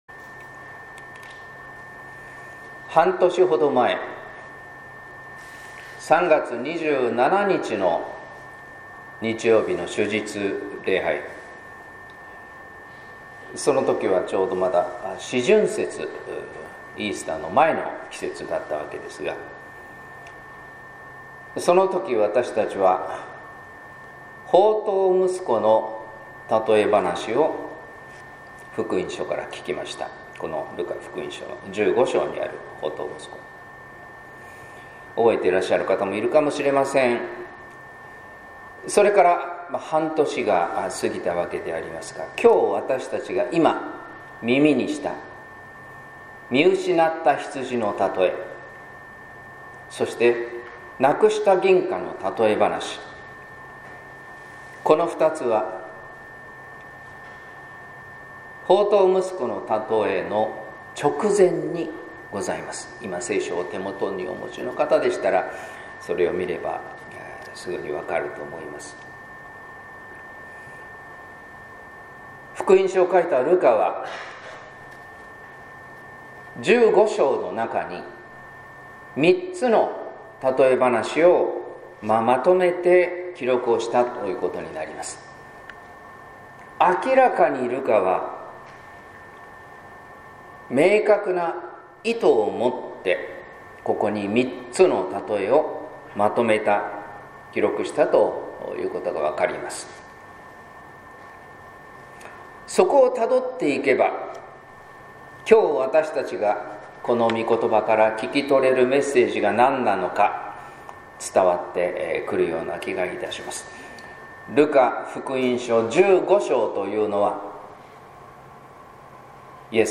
説教「見失った羊、なくした銀貨」（音声版）